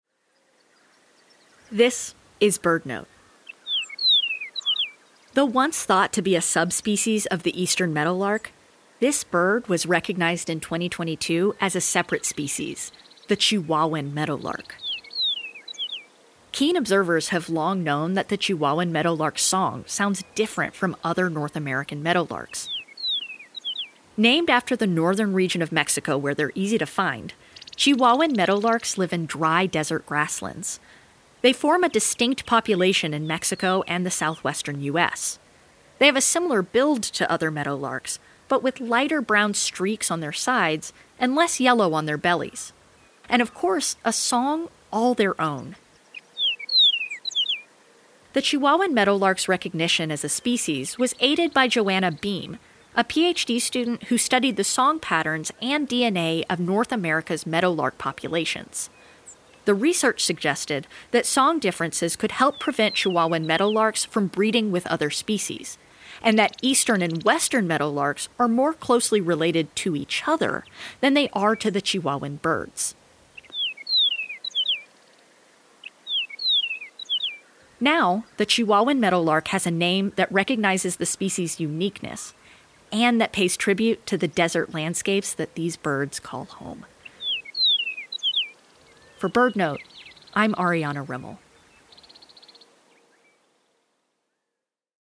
In 2022, ornithologists recognized the Chihuahuan Meadowlark as a separate species rather than a subspecies of the Eastern Meadowlark. Named after the northern region of Mexico where they’re easy to find, Chihuahuan Meadowlarks live in dry desert grasslands. They form a distinct population in Mexico and the southwestern U.S., and have a song that sets them apart from other meadowlarks.